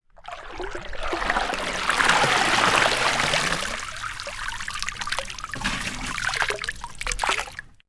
Water_27.wav